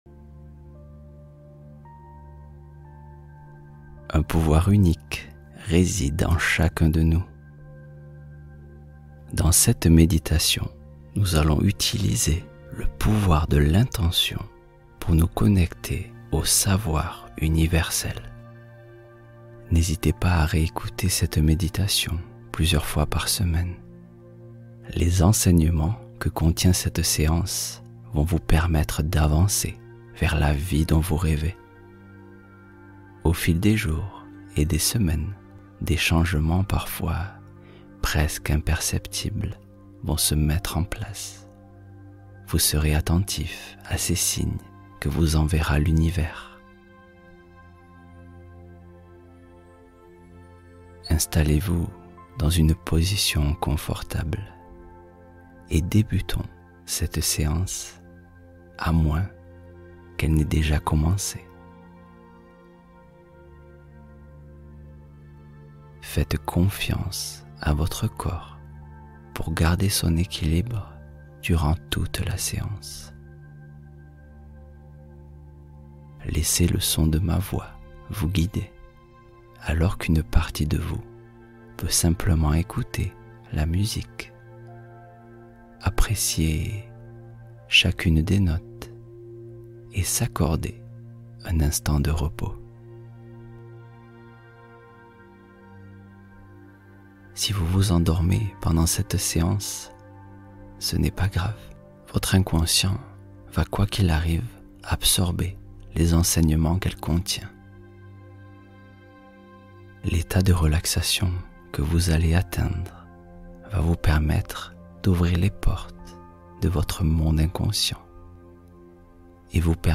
Relaxation Guidée